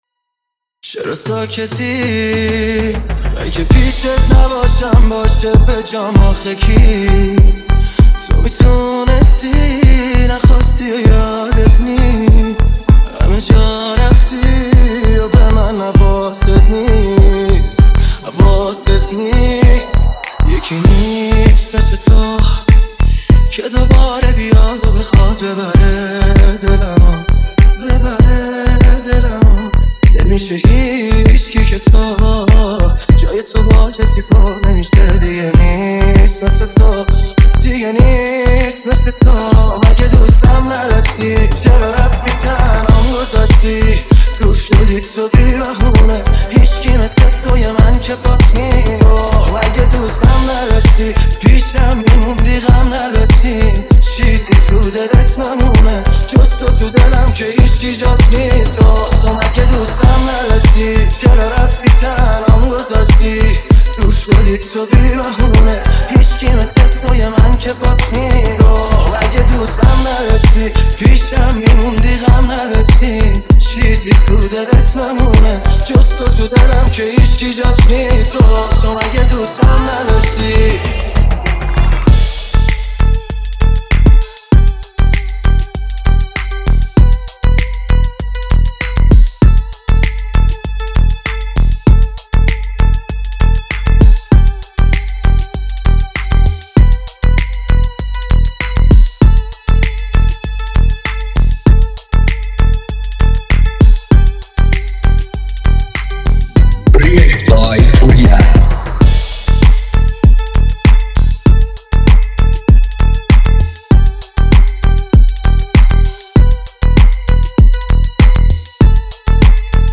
ریمیکس تند سیستمی بیس دار شاد جدید لینک دانلود مستقیم